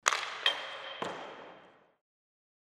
Звуки спорта
Звук удара шайбы о штангу во время хоккейного матча